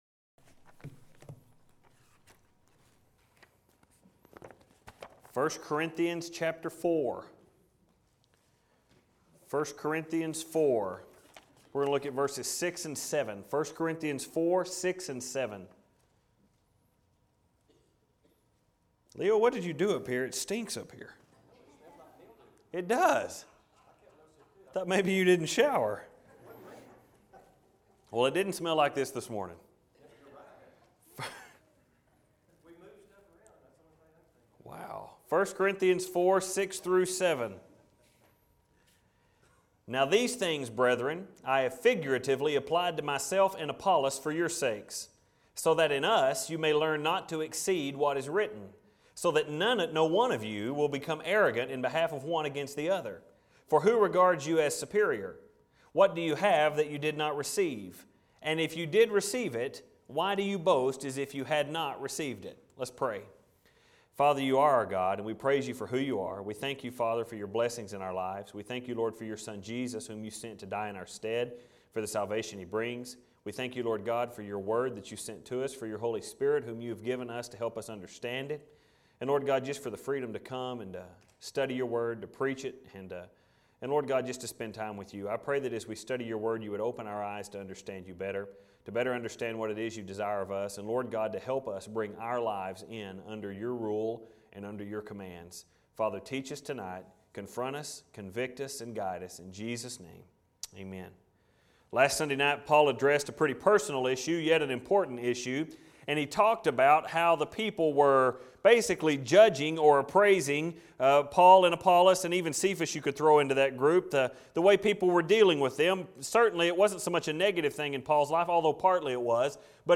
1st Corinthians Faulty Judgment - First Baptist Church Spur, Texas
Filed Under: Sermons Tagged With: Corinthians